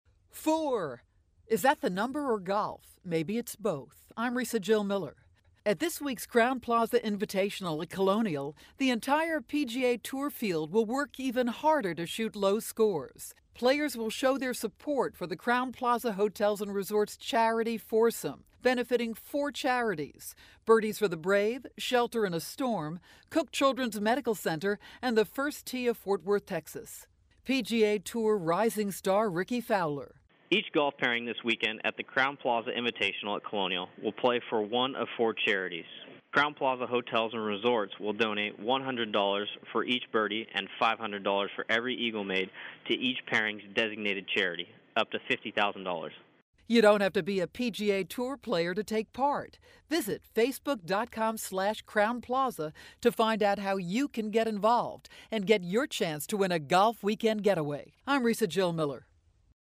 May 23, 2012Posted in: Audio News Release